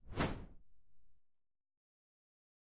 嗖嗖嗖 "的短中
描述：一个简单的飞快的效果。短而中等。
标签： 旋风 飞控 空气 速度快 阵风 传递由嗖
声道立体声